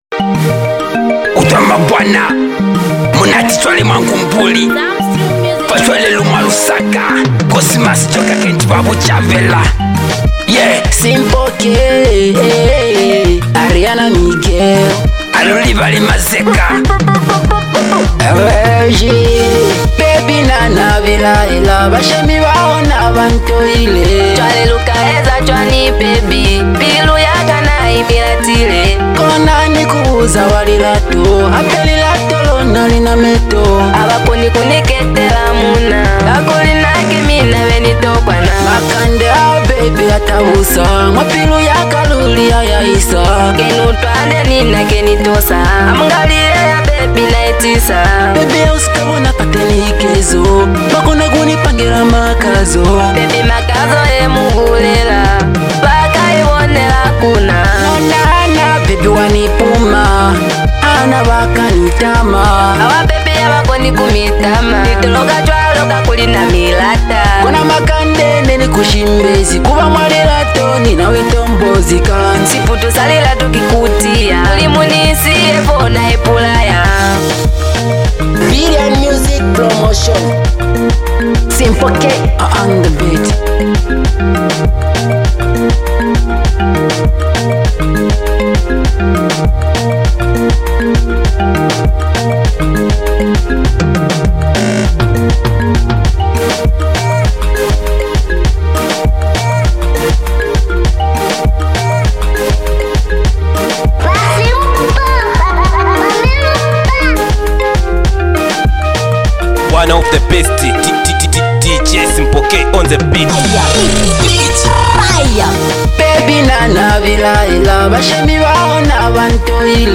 With smooth melodies, relatable lyrics, and rich production
Afro-inspired sounds with local influence